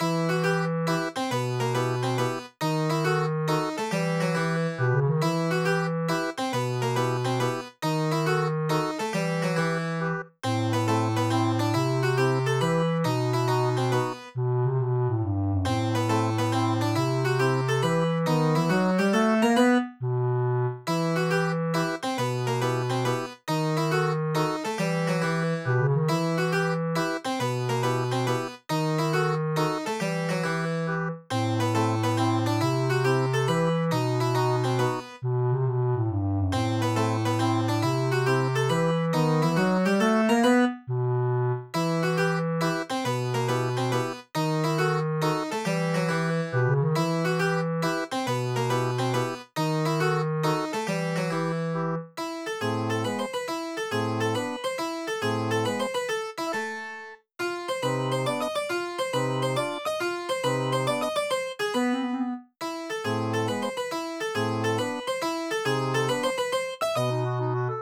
笑顔の裏で何を考えているのか…チェンバロが不気味に笑います
無限ループ暗い